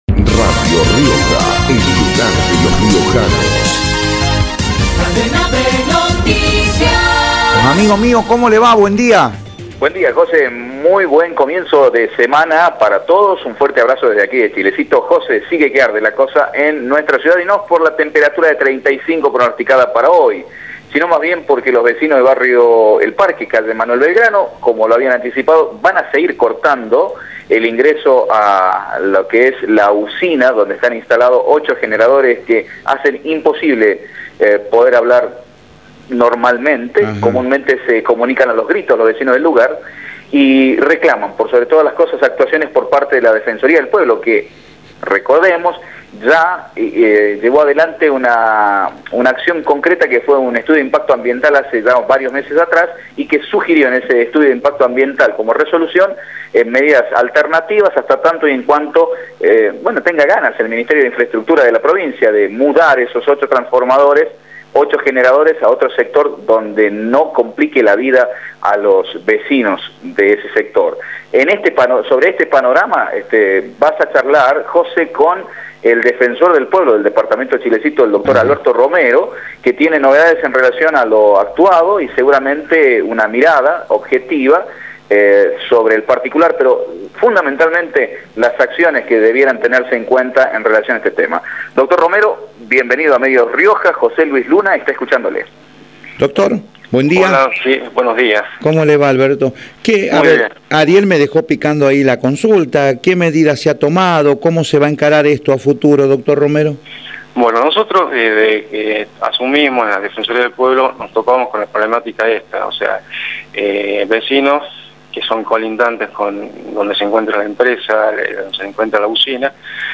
Alberto Romero, Defensor del Pueblo de Chilecito, por Radio Rioja